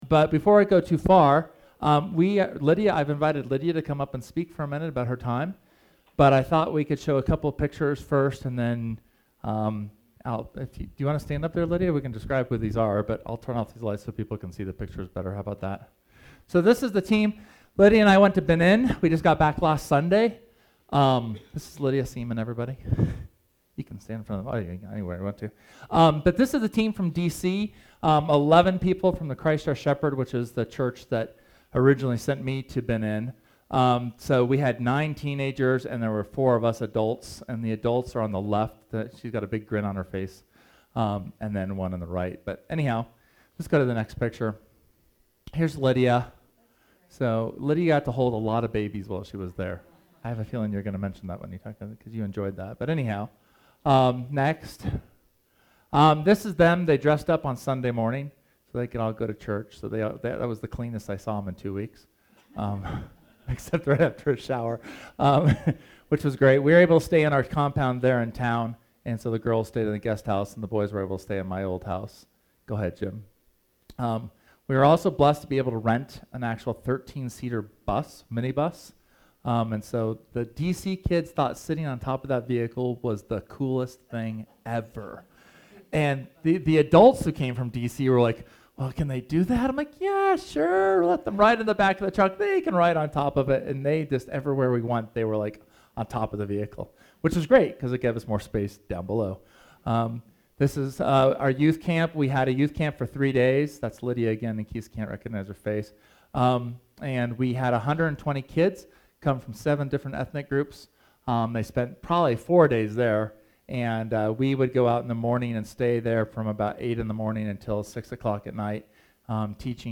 SERMON: Fear